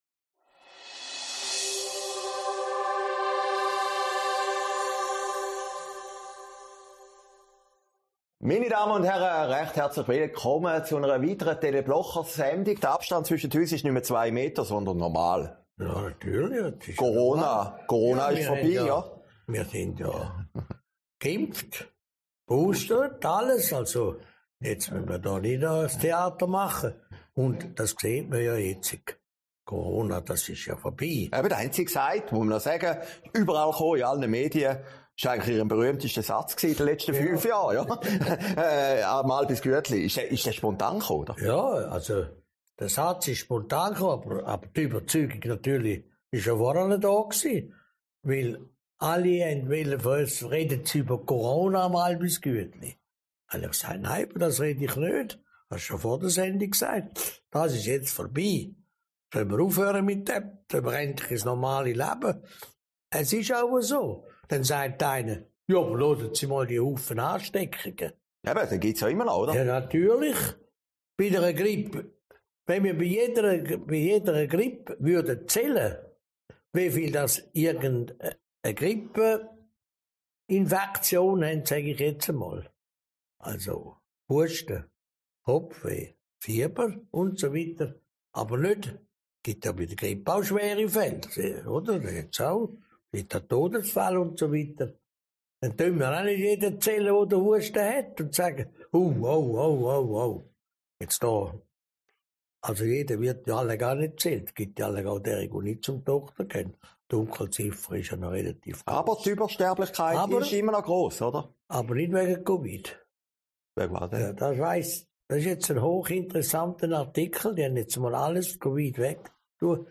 Sendung vom 28. Januar 2022, aufgezeichnet in Herrliberg